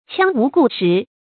羌無故實 注音： ㄑㄧㄤ ㄨˊ ㄍㄨˋ ㄕㄧˊ 讀音讀法： 意思解釋： 指詩文不用典故或沒有出處，也比喻沒有根據。